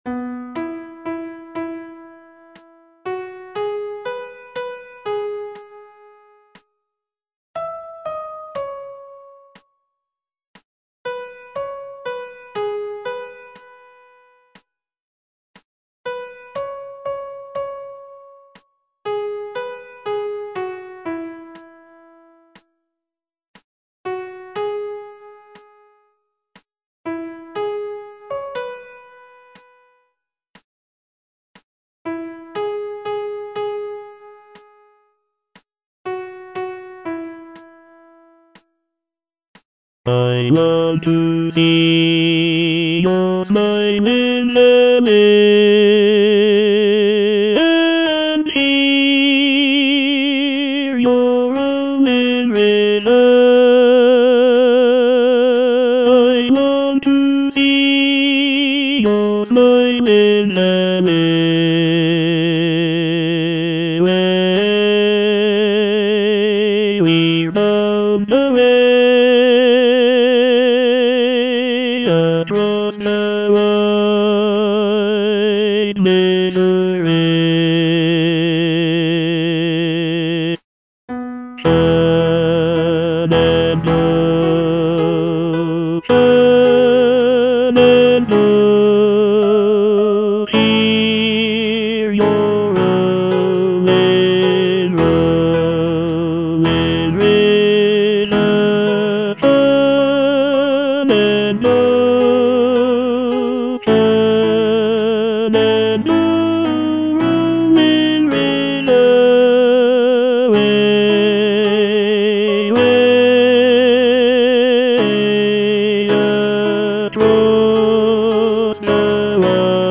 Tenor II (Voice with metronome)
traditional American folk song